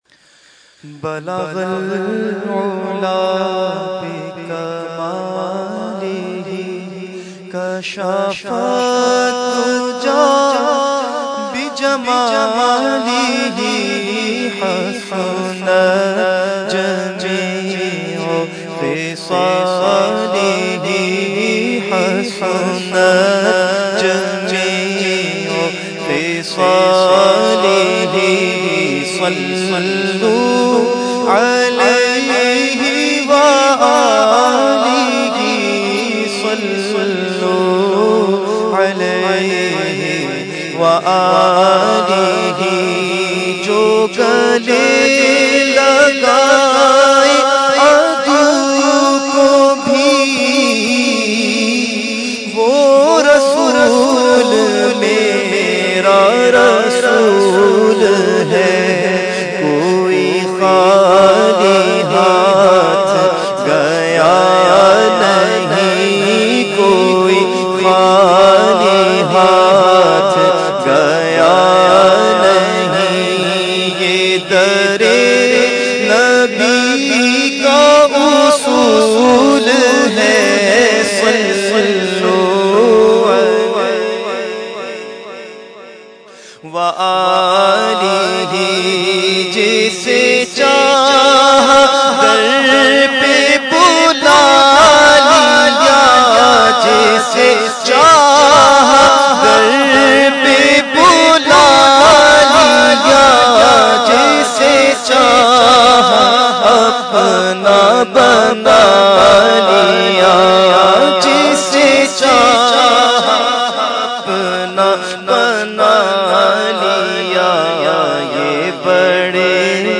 Category : Manqabat | Language : UrduEvent : Mehfil 11veen Liaqatabad 17 February 2014